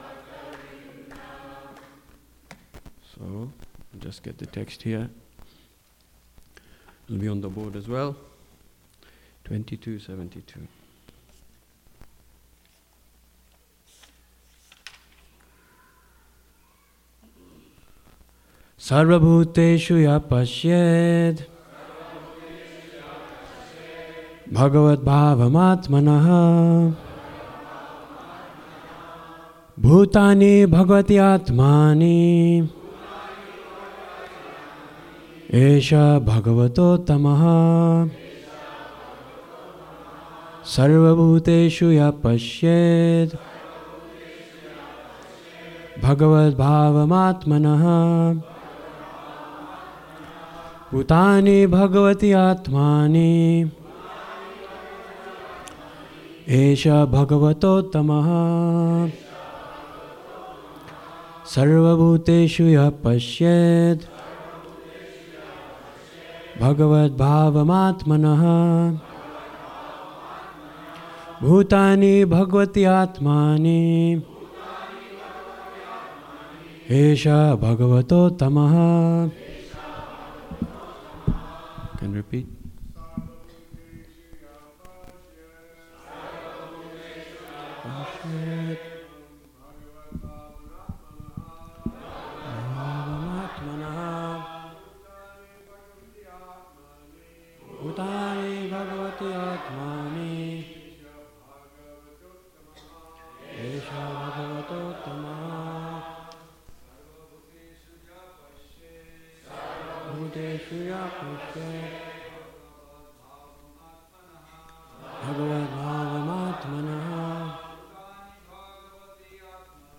2024 at the Hare Krishna temple in Alachua, Florida. Sri Caitanya Caritamrta is a sacred Vaishnava text from India that narrates the glorious birth and life of Sri Caitanya Mahaprabhu (regarded as the Yuga Avatar of Lord Sri Krishna) and His devotees.